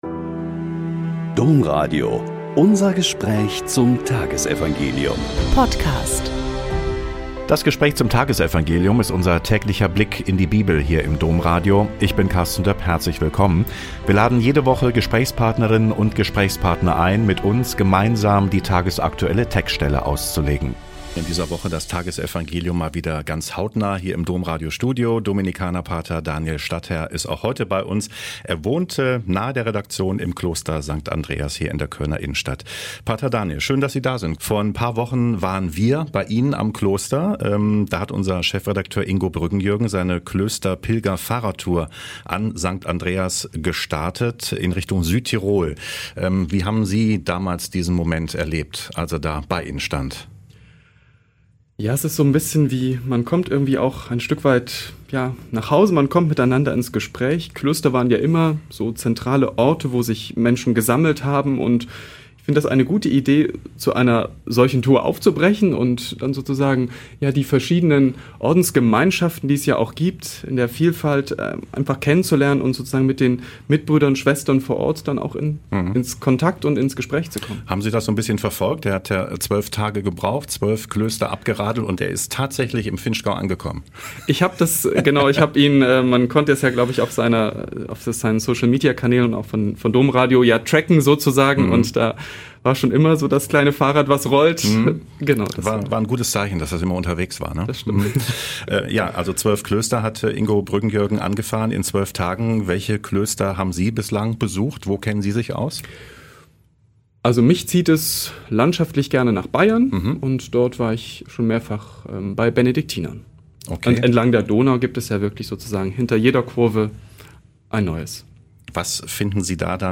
Mt 25,1-13 - Gespräch